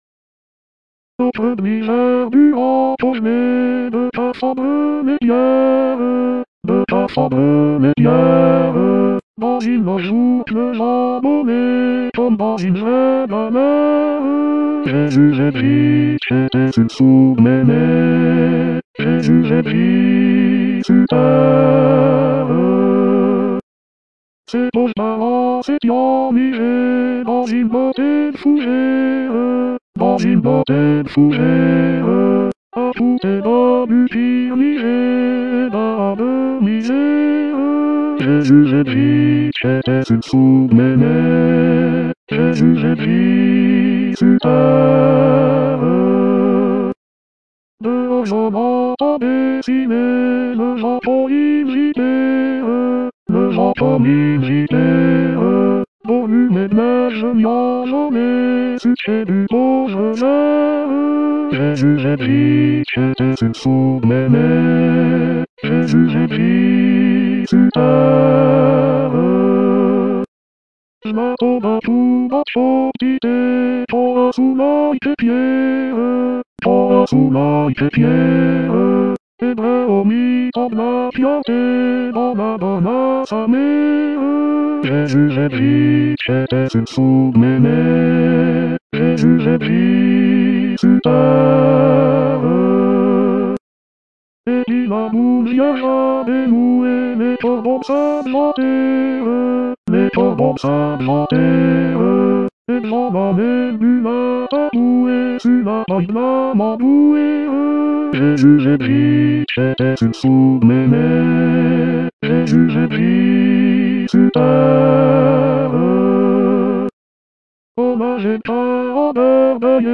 Noe--l-Au-creux-04-Hommes.mp3